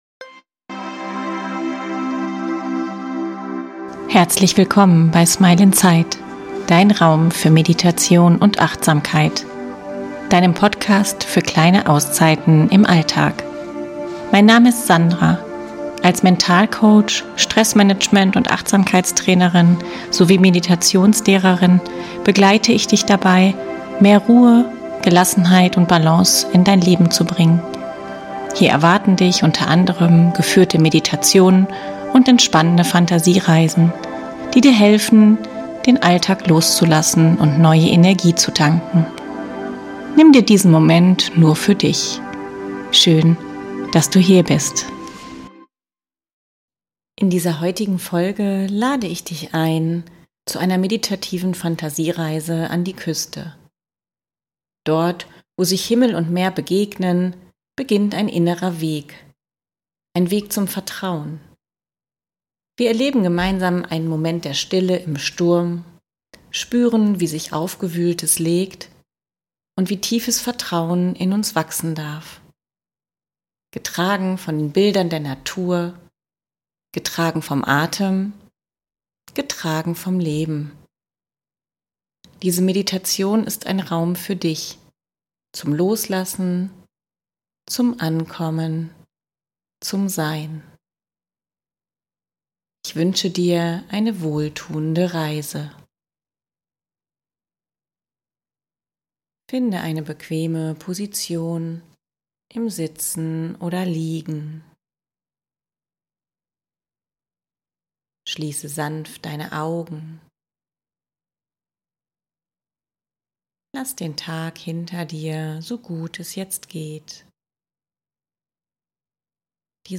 In dieser Folge lade ich dich ein zu einer meditativen Fantasiereise an die Küste.